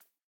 rabbit_hop1.ogg